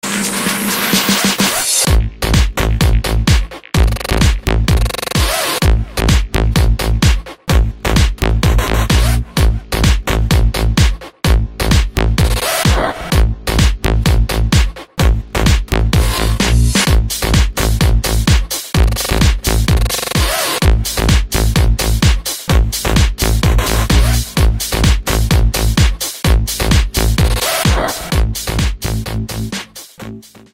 Танцевальные рингтоны
Рингтоны без слов
Рингтоны техно
EDM , electro house